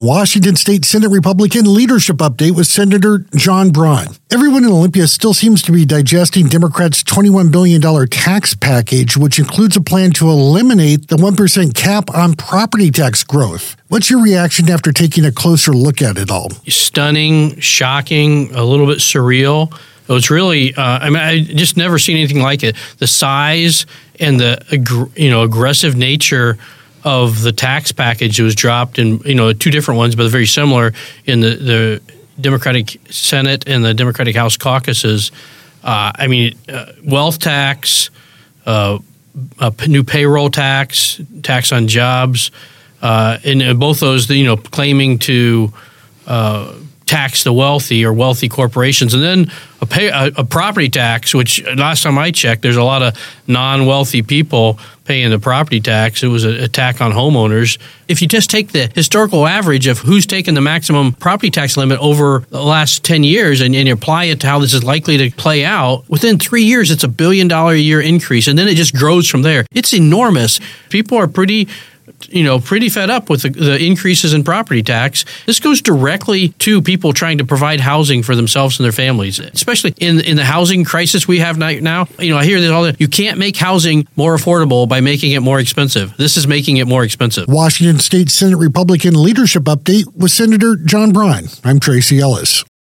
AUDIO: Washington State Senate Republican leadership update with Senator John Braun.